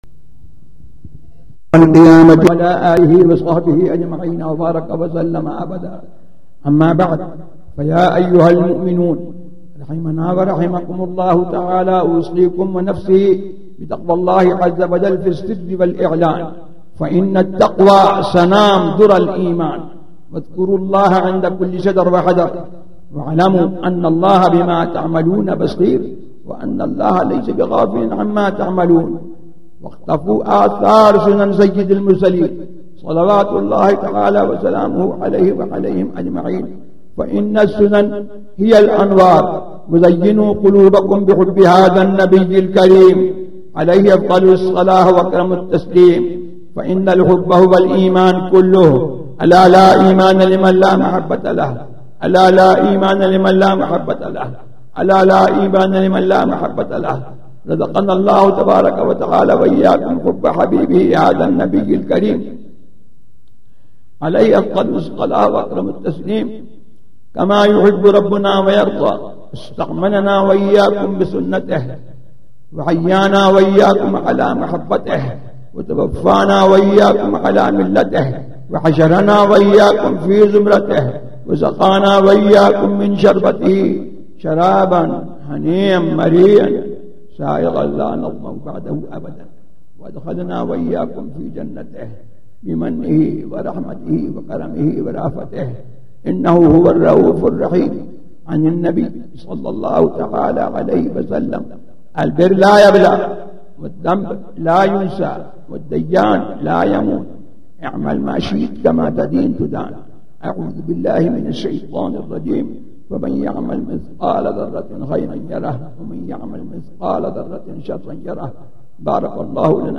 Khutba-e-Jumuah at DarusSalam
خطبہ
تقاریر